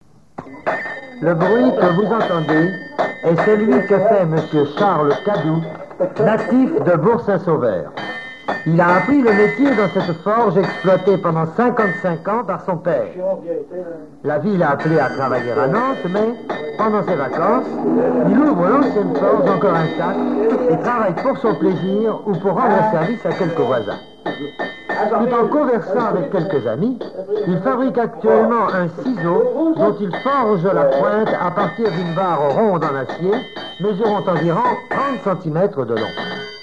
Mots Clé forgeron, forge
reportage et montage sonore relatif à Saint-Sauveur
Catégorie Témoignage